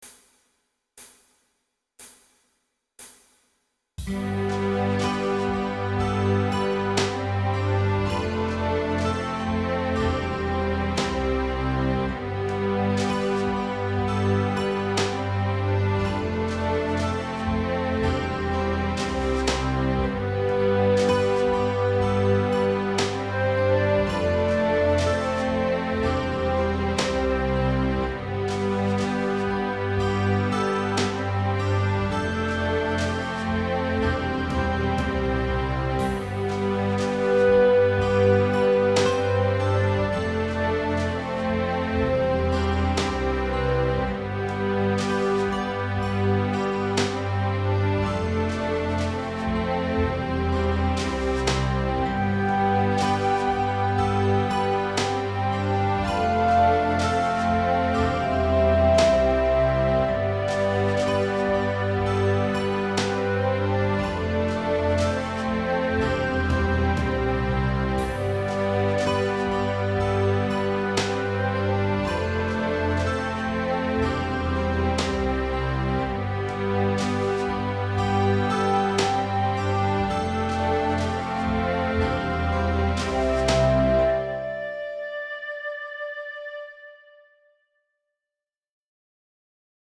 Tonalidade: sol mixolidio; Compás: 4/4